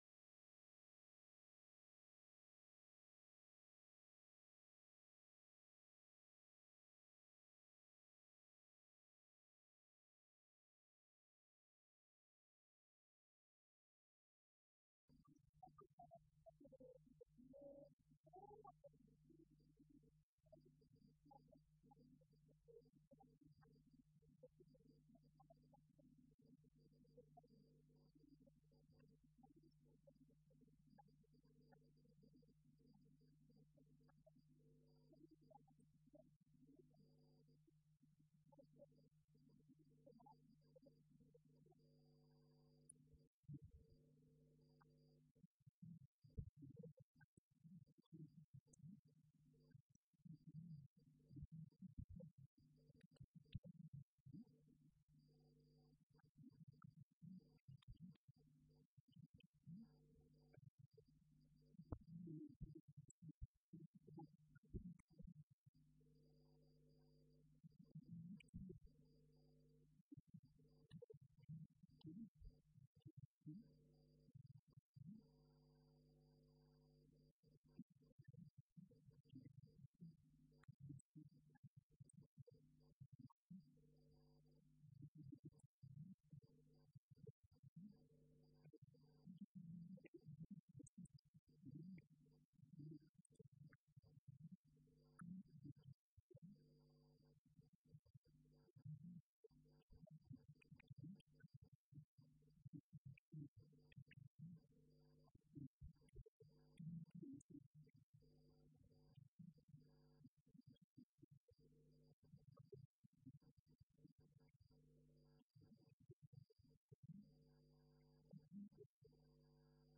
Colloque L’Islam et l’Occident à l’époque médiévale.